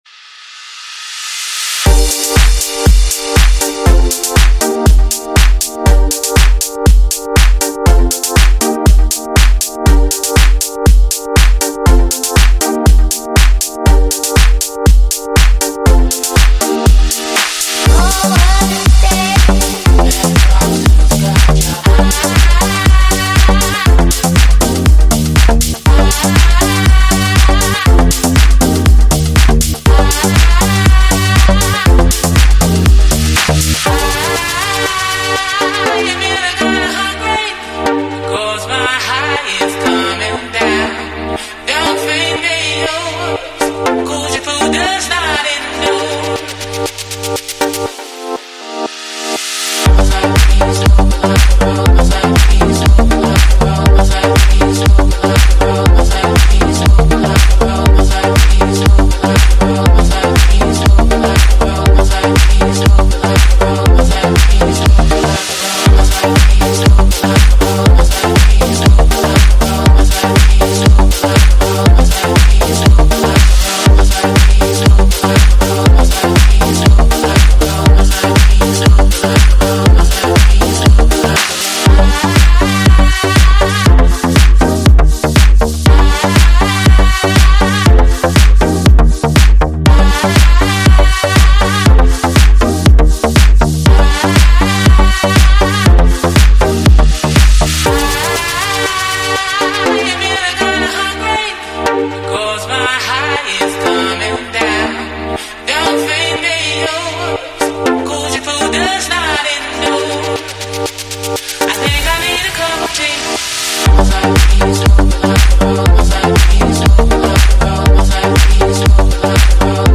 эмоциональная поп-баллада